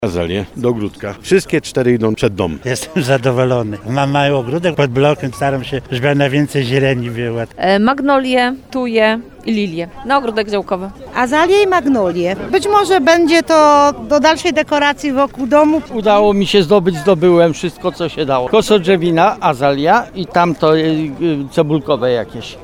1000 sadzonek ozdobnych krzewów rozdano na rynku w Nowym Sączu.
– Jestem zadowolony, bo mam mały ogródek pod blokiem i staram się, żeby było tam jak najwięcej zieleni – dodał starszy pan z jednego z nowosądeckich osiedli.